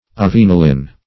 Search Result for " avenalin" : The Collaborative International Dictionary of English v.0.48: Avenalin \A*ven"a*lin\, n. [L. avena eats.]